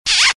Звуки поцелуя
Чмокнул